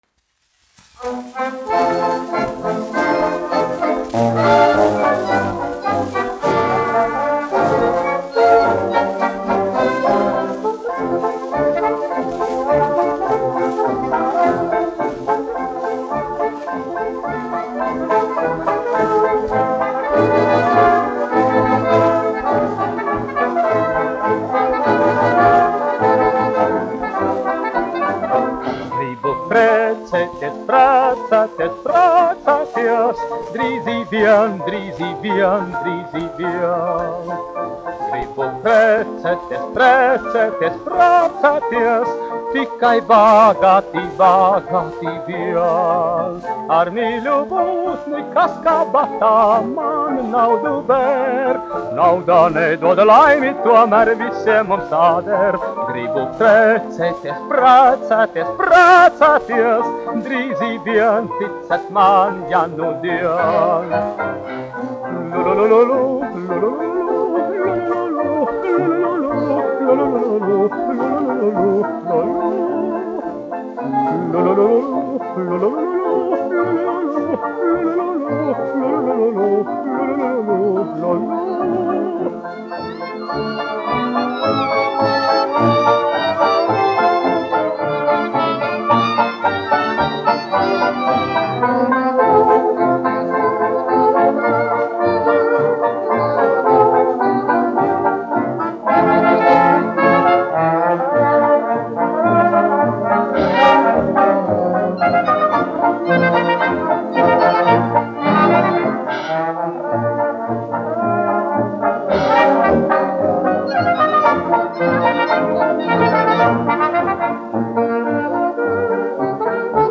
1 skpl. : analogs, 78 apgr/min, mono ; 25 cm
Fokstroti
Kinomūzika
Skaņuplate